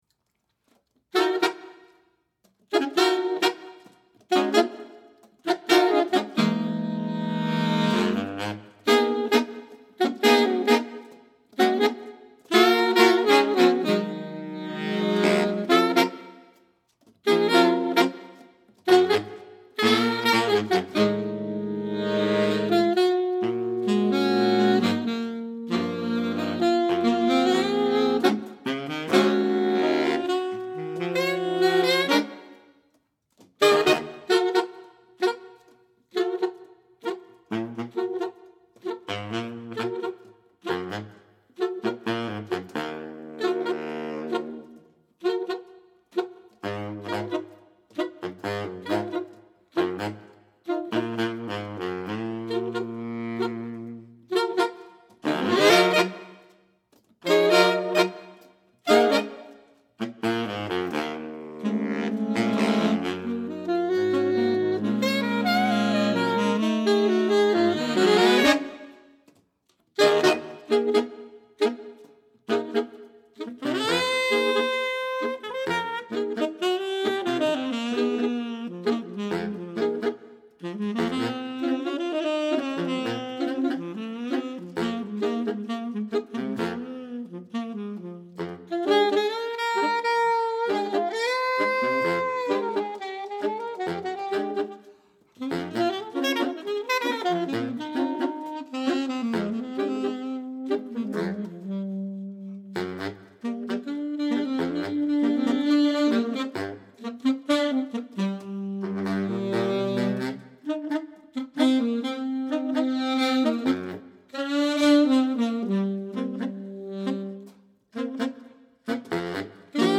Composer: Negro Spiritual
Voicing: Saxophone Quartet